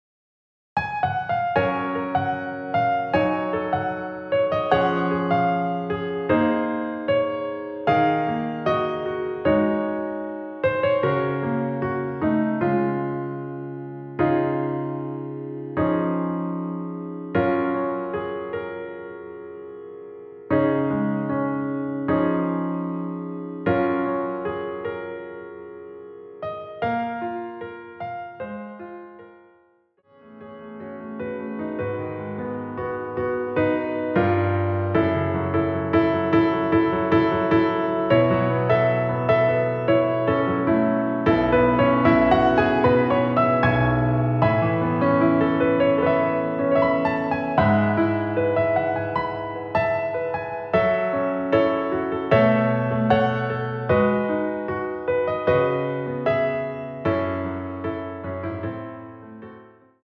원키에서(+5)올린 MR입니다.
Db
앞부분30초, 뒷부분30초씩 편집해서 올려 드리고 있습니다.